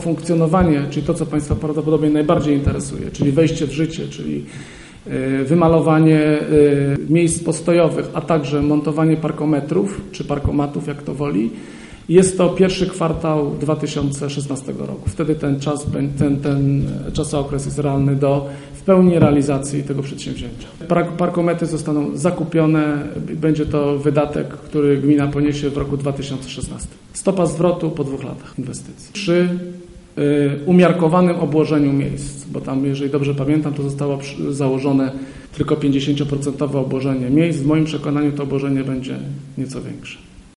Temat poruszyliśmy na konferencji prasowej. Jak poinformował nas burmistrz Robert Luchowski najprawdopodobniej płatne strefy parkingowe pojawią się w Żninie w pierwszym kwartale przyszłego roku. Opłaty pobierane będą za pomocą parkometrów, a nie jak do tej pory przez inkasentów.